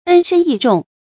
恩深义重 ēn shēn yì zhòng 成语解释 恩惠、情义极为深重。